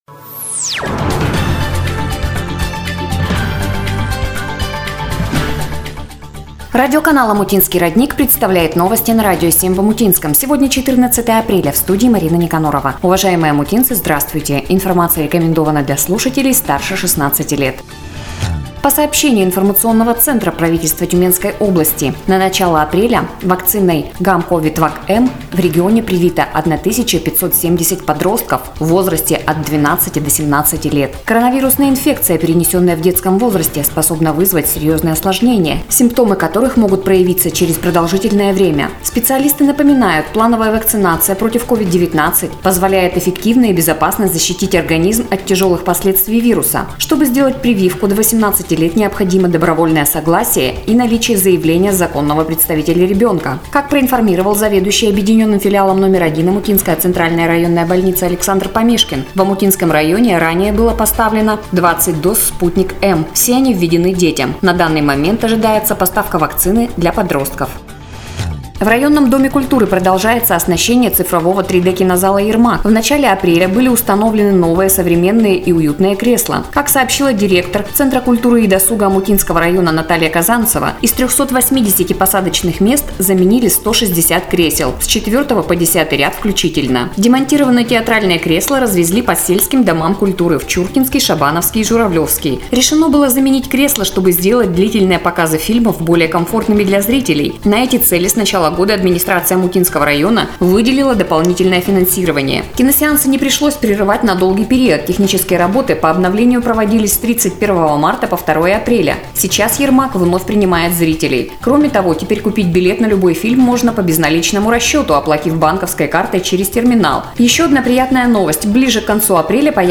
"Для детей старше 16 лет" Новости! 1.